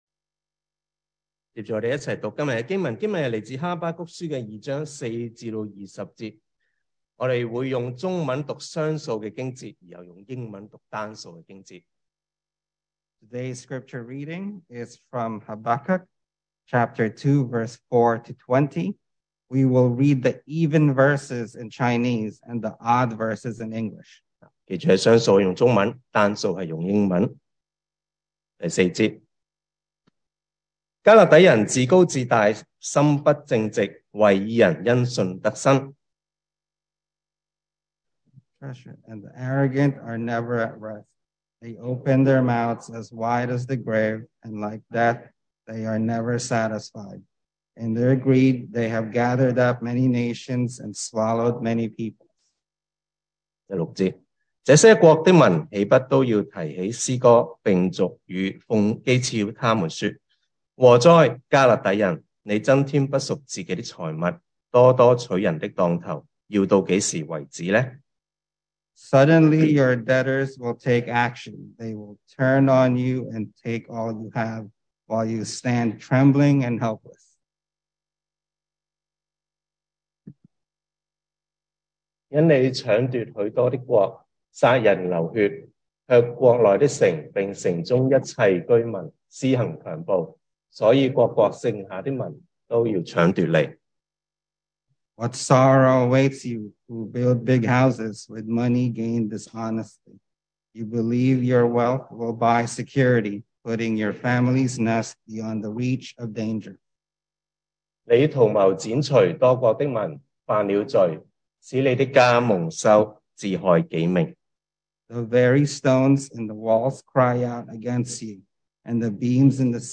2023 sermon audios
Service Type: Sunday Morning